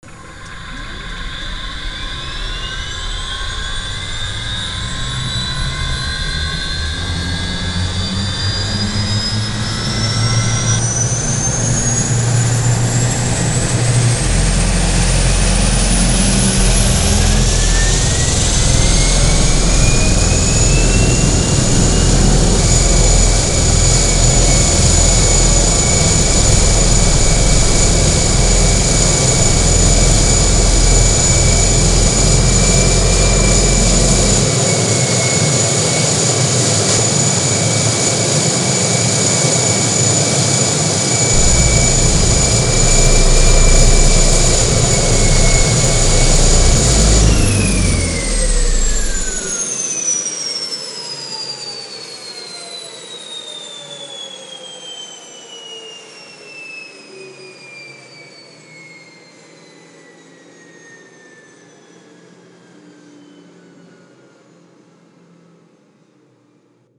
ringtone-engine.mp3
It’s not a bad attempt; it sounds pretty convincing.